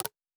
pgs/Assets/Audio/Fantasy Interface Sounds/UI Tight 14.wav at master
UI Tight 14.wav